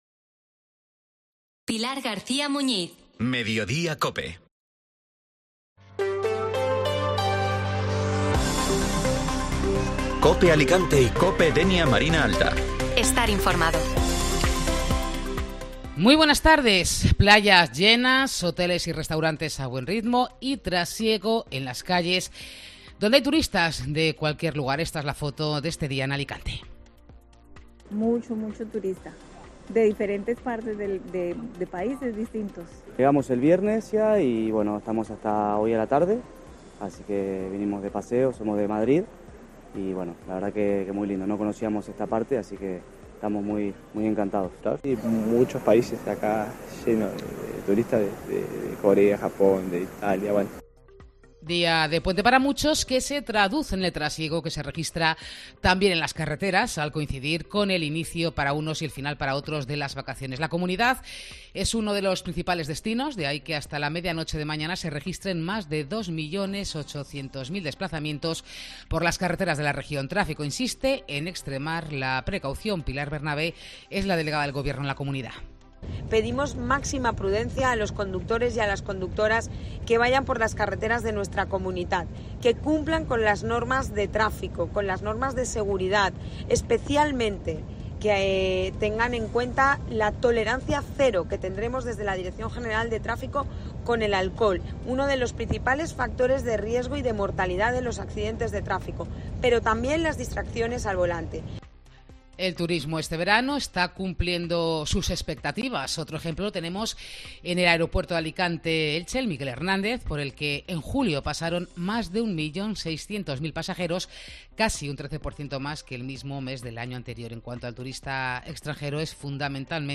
Informativo Mediodía Cope Alicante (Lunes 14 de Agosto)